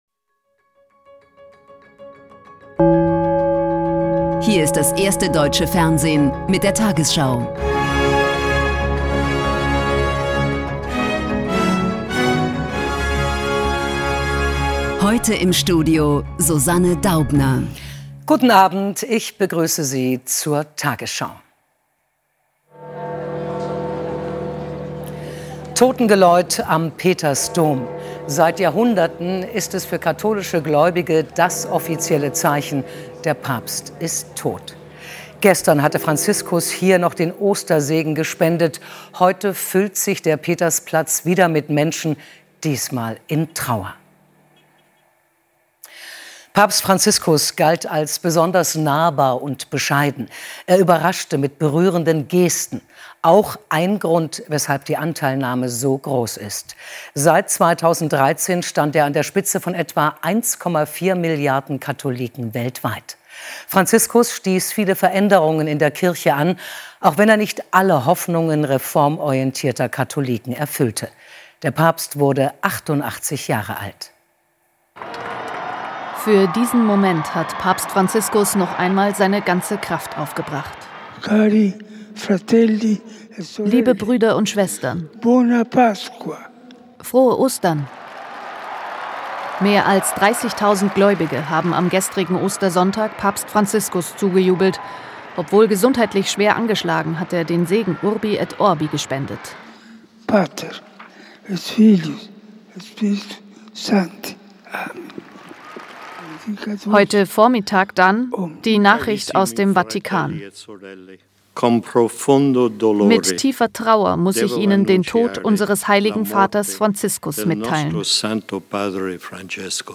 Die 20 Uhr Nachrichten von heute zum Nachhören. Hier findet ihr immer die aktuellsten und wichtigsten News.